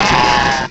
-Replaced the Gen. 1 to 3 cries with BW2 rips.
cranidos.aif